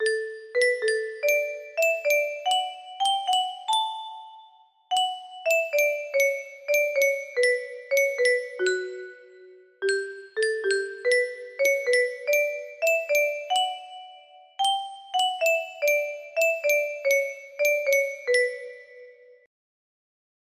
This dumbbbb thing music box melody